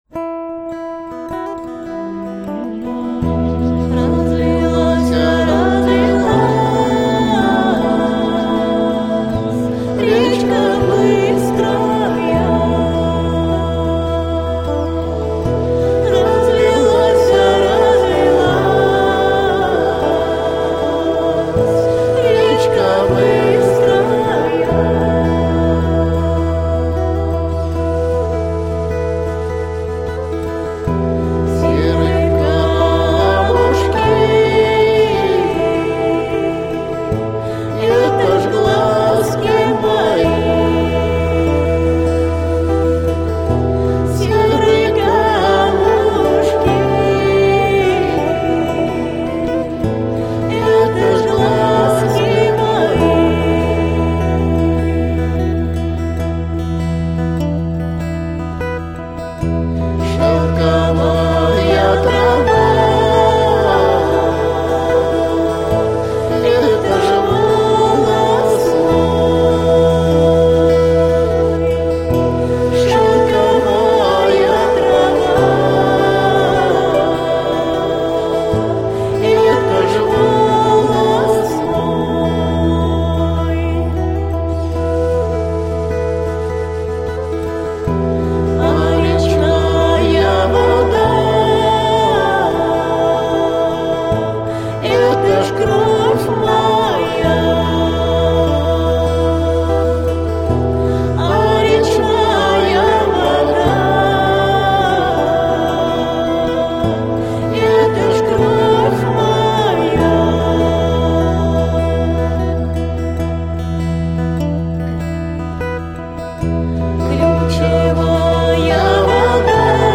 slavyanskaya_muzyka_ochen_horoshaya_pesnya.mp3